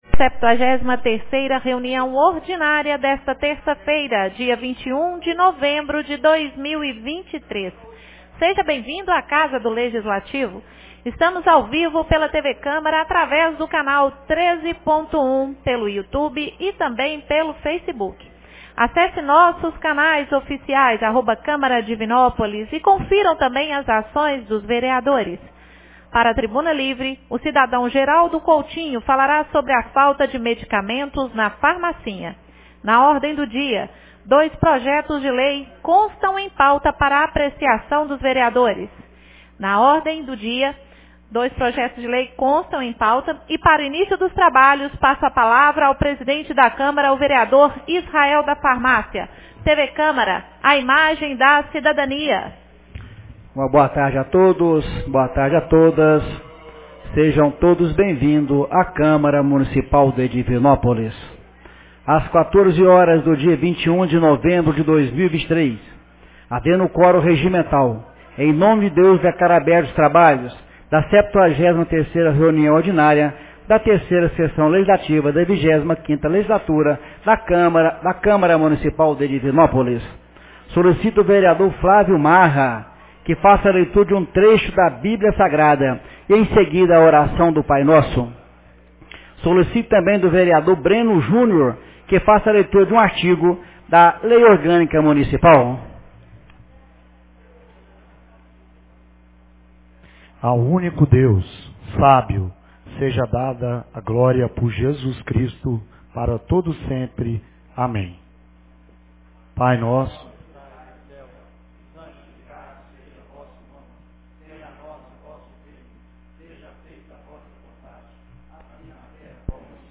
73ª Reunião Ordinária 21 de novembro de 2023